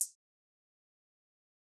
The Birds Pt. 2 Hi-Hat.wav